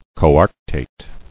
[co·arc·tate]